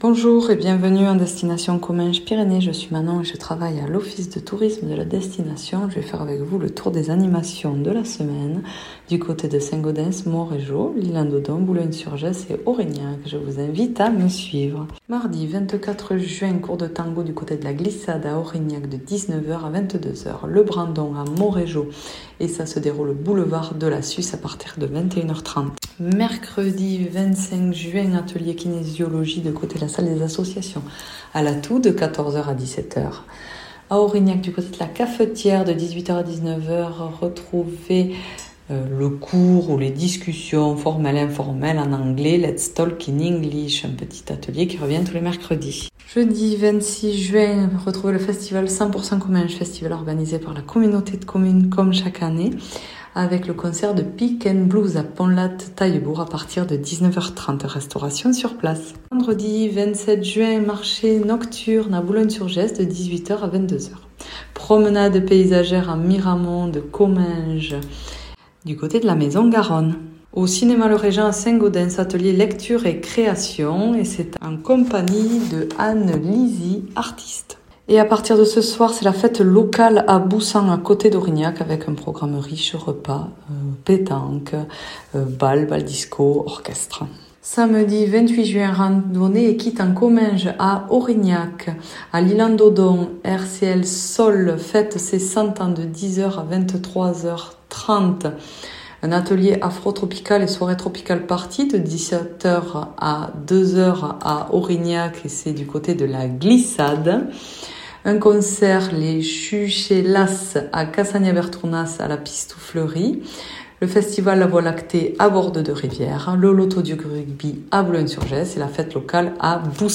Journaliste